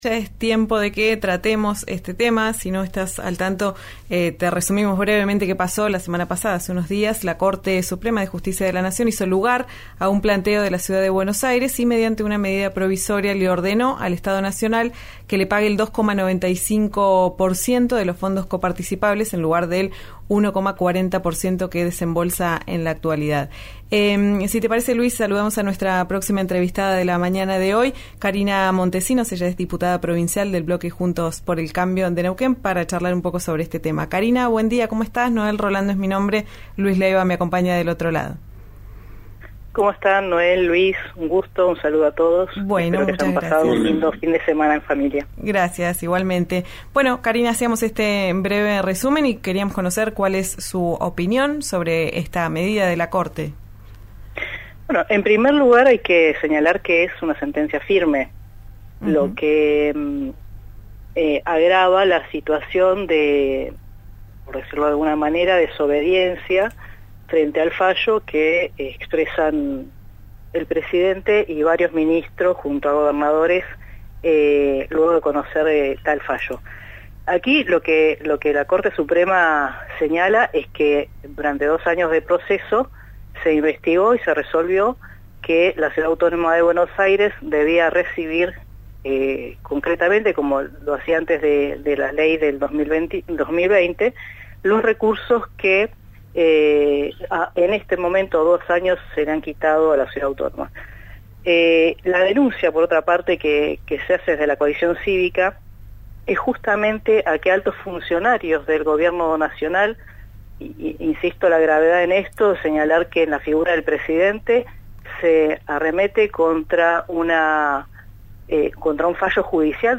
Habló con «Ya es tiempo» por RÍO NEGRO RADIO.
Escuchá a Karina Montecinos, diputada provincial de Neuquén, por CC-ARI en «Ya es tiempo» por RÍO NEGRO RADIO: